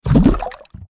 tt_s_ara_cmg_waterCoolerFill.ogg